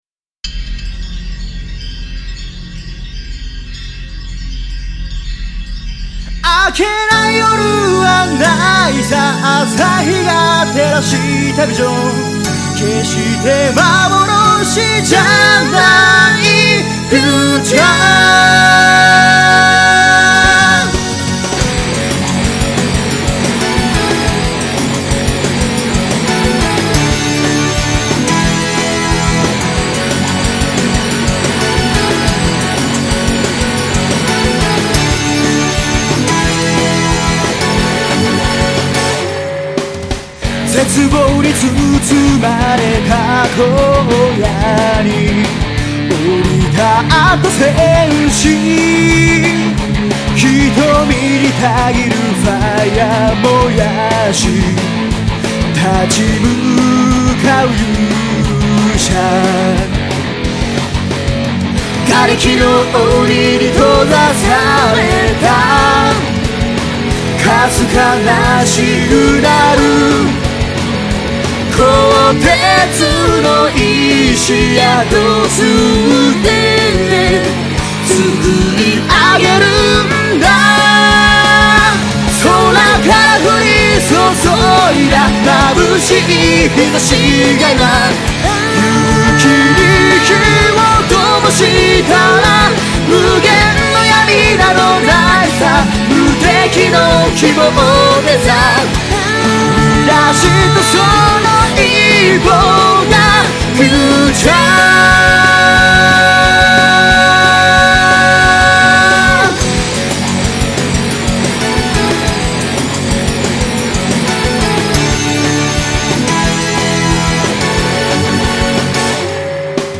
・自衛隊や世界中から集まってくれたレスキューの方々に捧げる、熱いロック調の曲です！